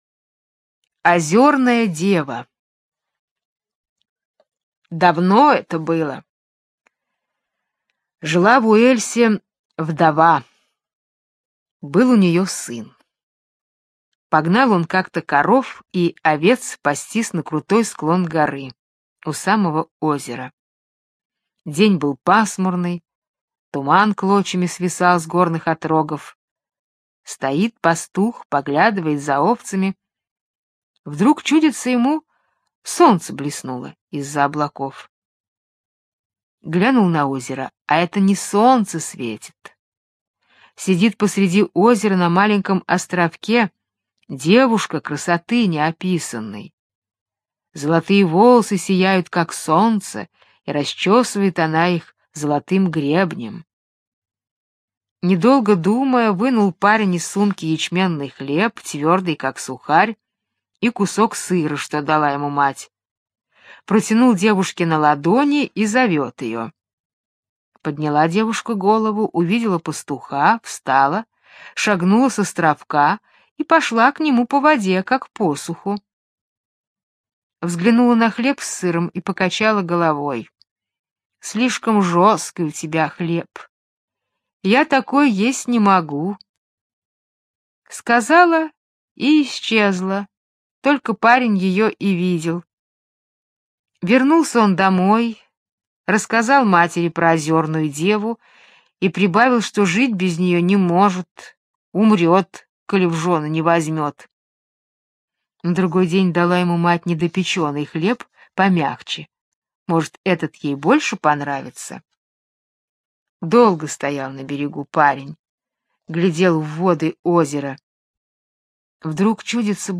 Озерная дева - британская аудиосказка - слушать онлайн